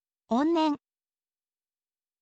on nen